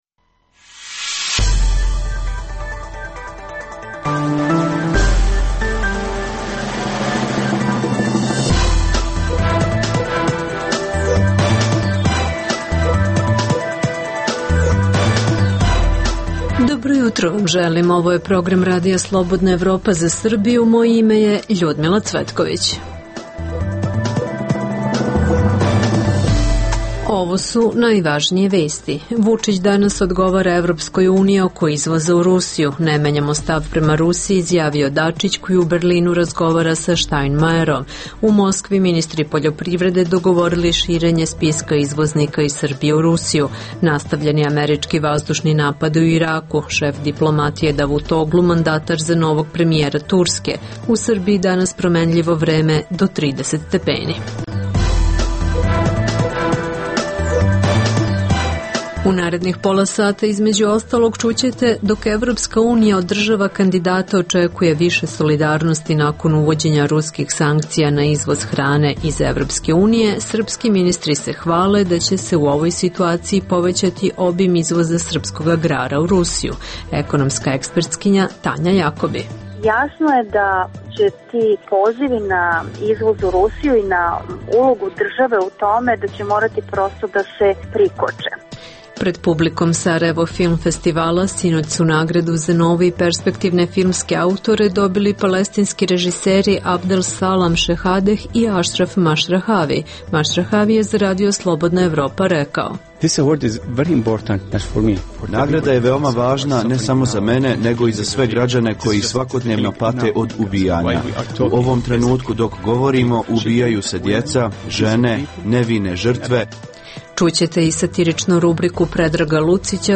Emisija namenjena slušaocima u Srbiji koja sadrži lokalne, regionalne i vesti iz sveta te tematske priloge o aktuelnim dešavanjima priče iz svakodnevnog života. Rizikuju li vlasti u Srbiji “pucanj u sopstvenu nogu”, u pokušaju profitiranja od ruskih sankcija Evropi?